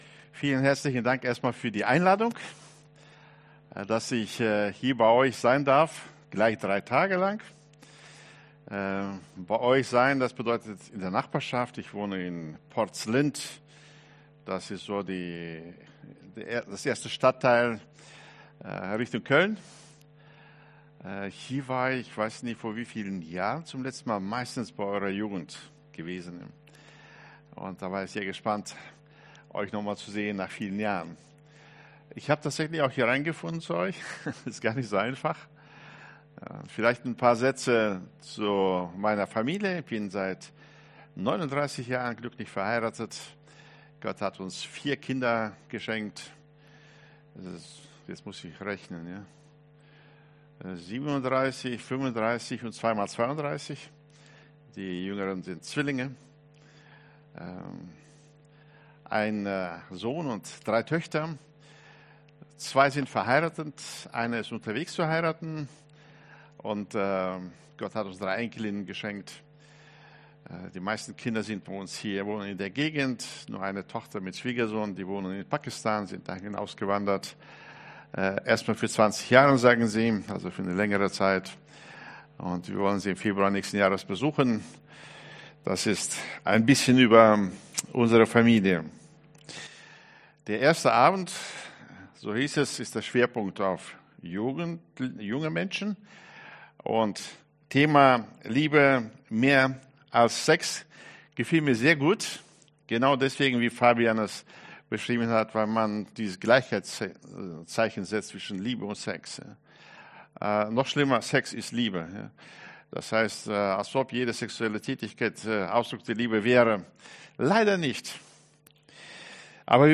November 2025 Liebe und Identität Prediger